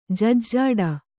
♪ jajjhāḍa